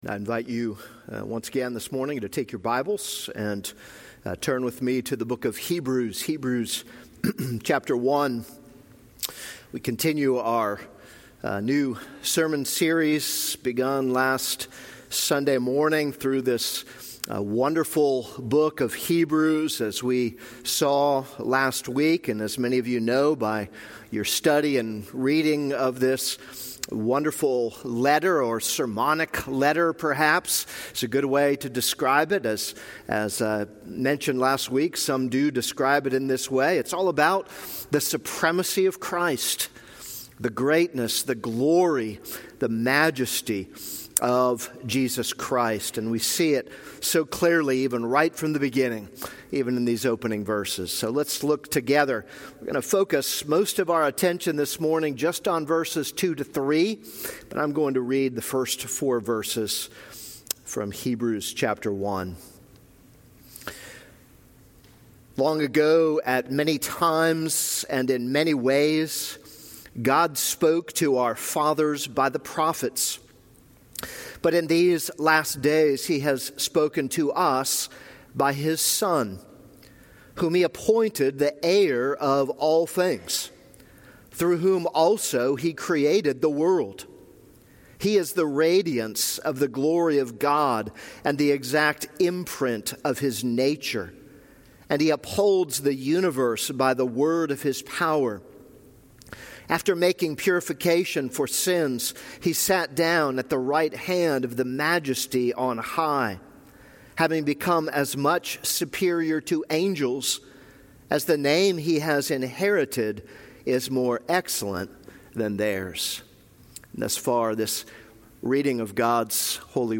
This is a sermon on Hebrews 1:2-4.